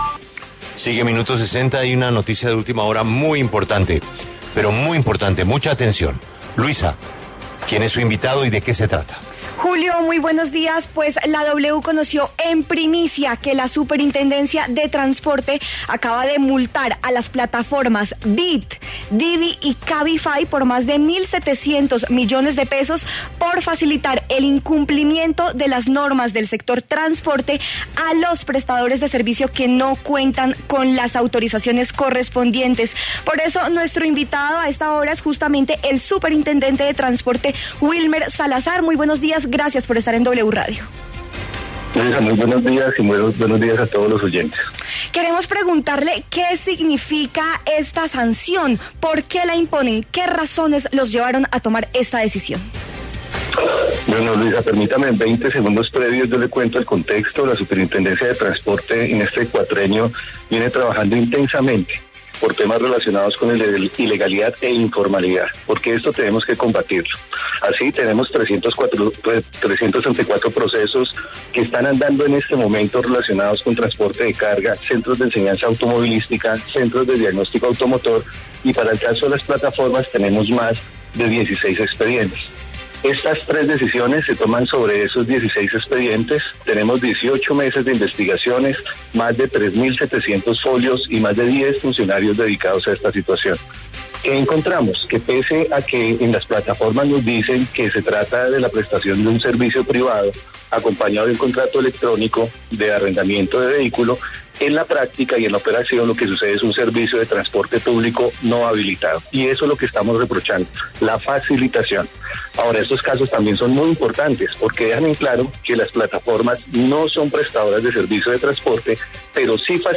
En diálogo con La W, el superintendente de Transporte, Wilmer Salazar, se pronunció sobre esta sanción en la que Beat, Didi y Cabify tendrán que hacer ajustes para seguir prestando el servicio.